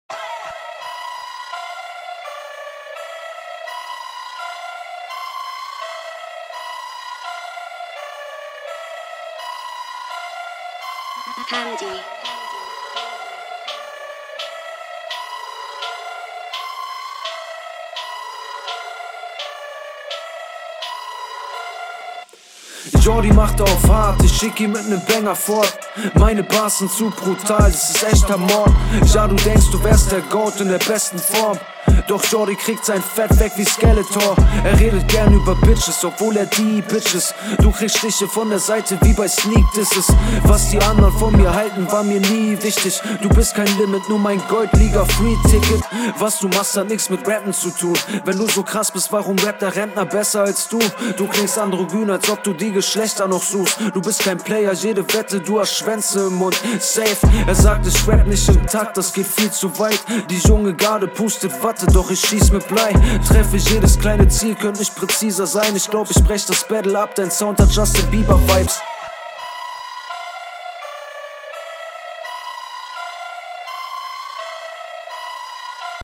Stimmeinsatz finde ich wieder cool.